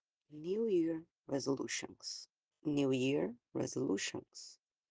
ATTENTION TO PRONUNCIATION: